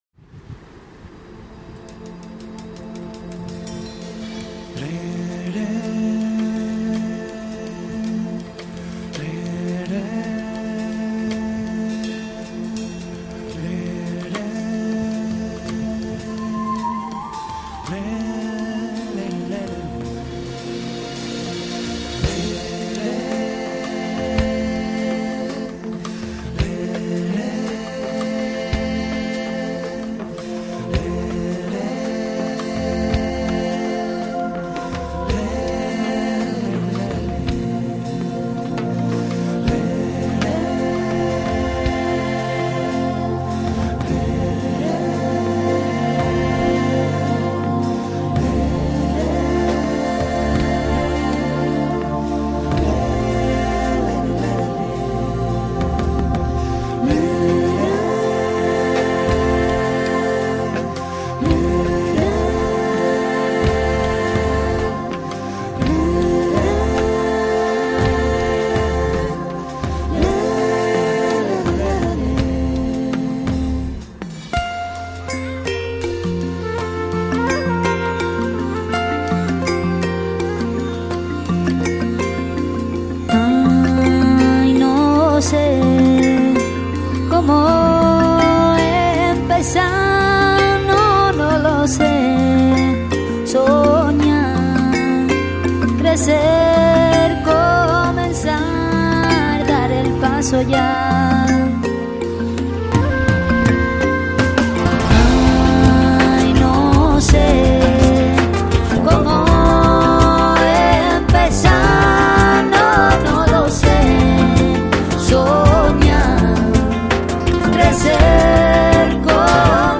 时而是清脆的吉他伴随着如天籁之声的吟唱，时而又有富有节奏的击掌和踢踏声
Genre: Chillout, Flamenco, New Age